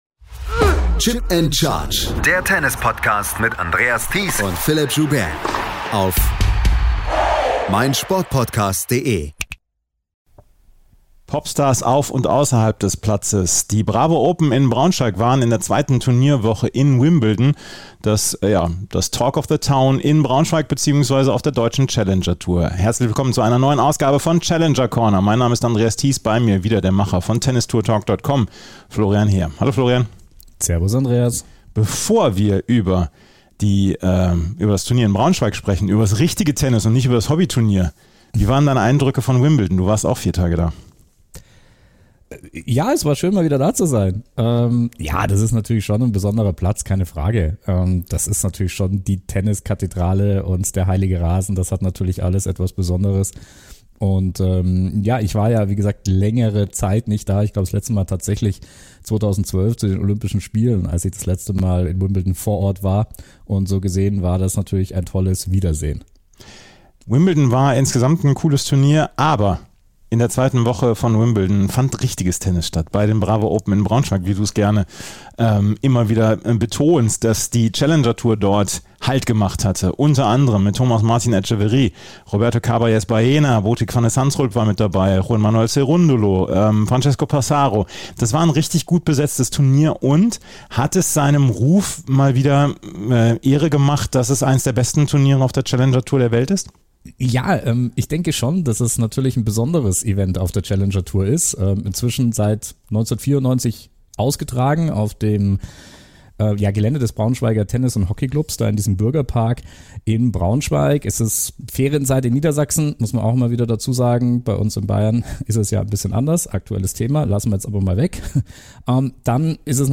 Neben einem exklusiven Backstage-Interview mit Alphaville-Leadsänger Marian Gold haben wir in der aktuellen ...